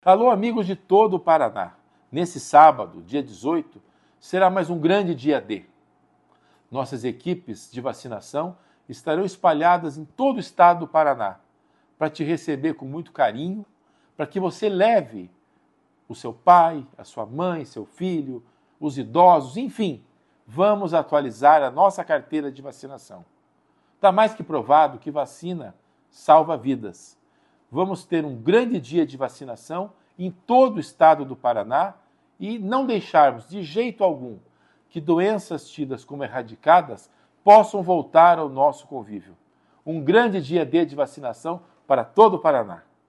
Sonora do secretário da Saúde em exercício, César Neves, sobre o dia D de multivacinação